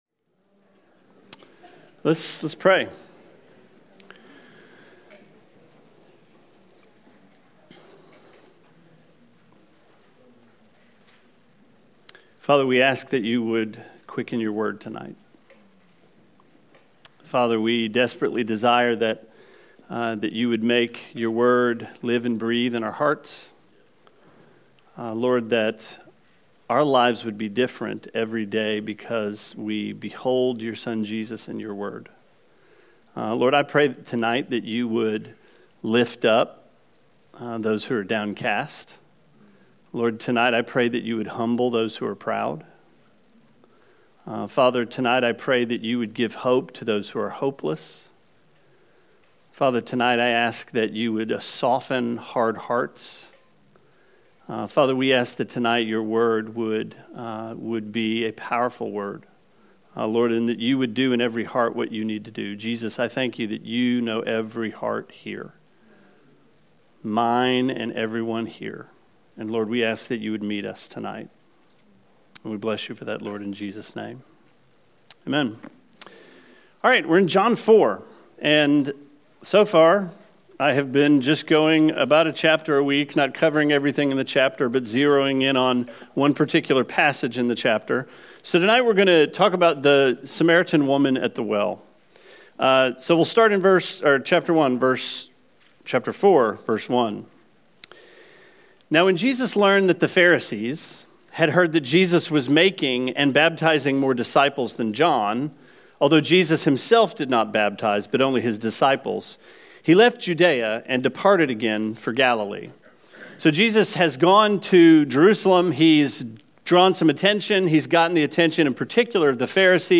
Sermon 09/28: John 4 – Trinity Christian Fellowship